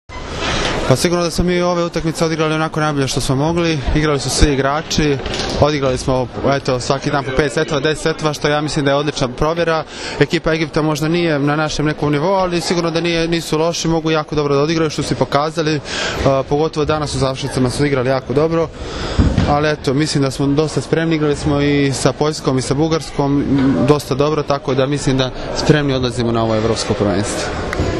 IZJAVA SAŠE STAROVIĆA